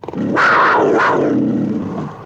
Animal 1.wav